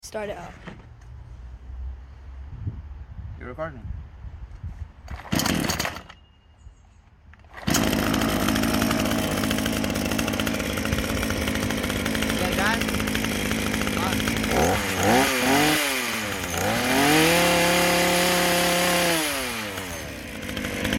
petrol chain saw 49cc 2hp sound effects free download